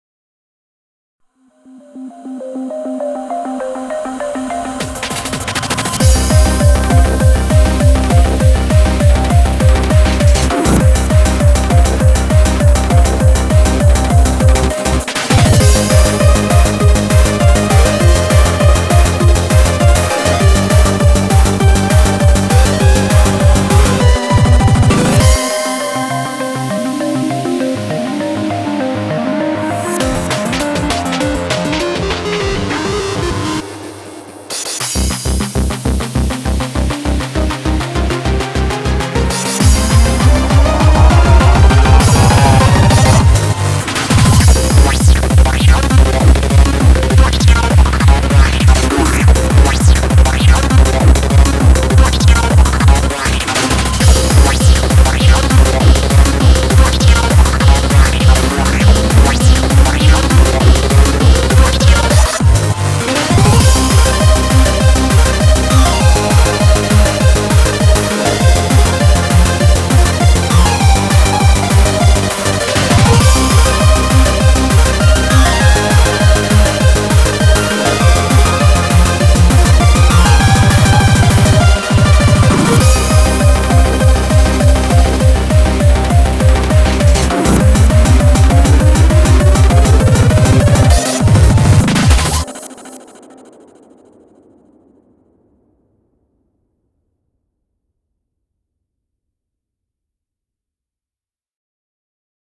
BPM100-400
Audio QualityPerfect (High Quality)